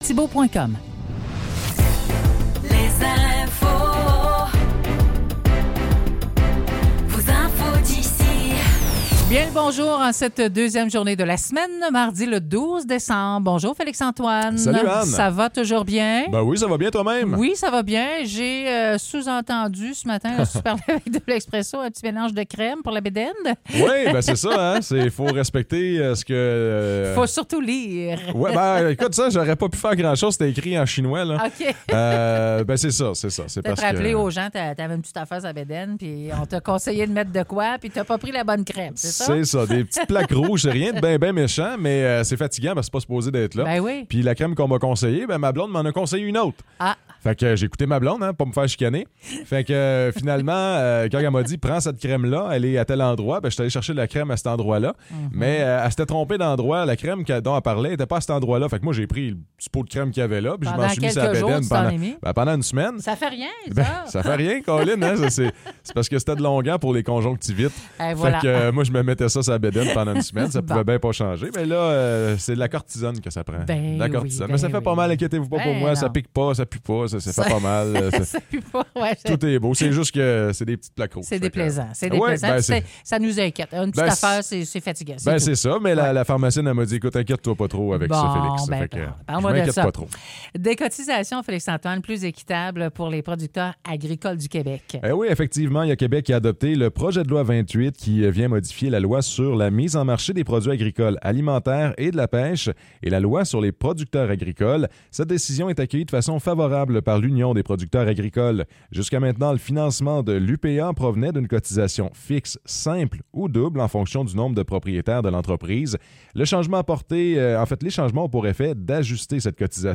Nouvelles locales - 12 décembre 2023 - 9 h